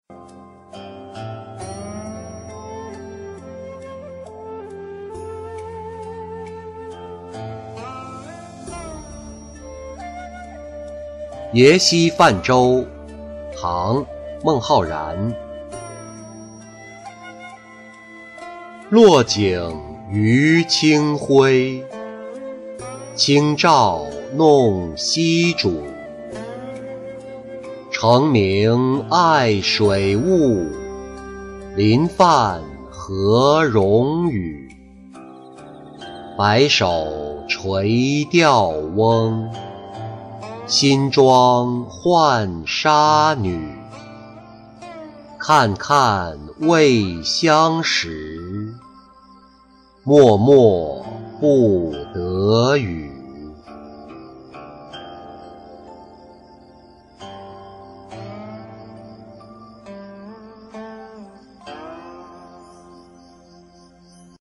耶溪泛舟-音频朗读